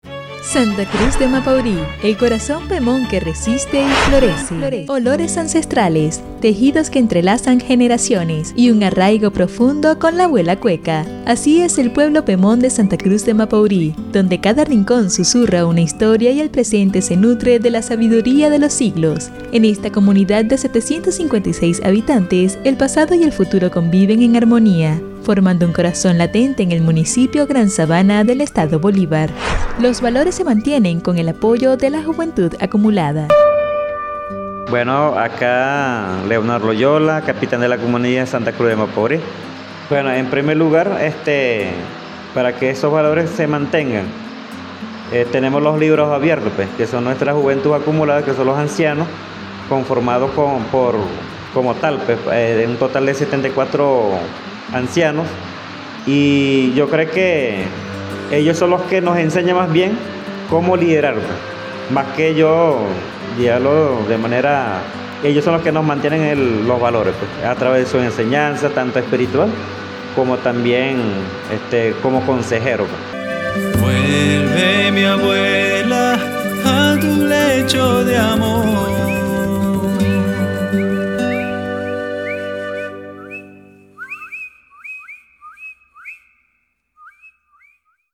Micros radiales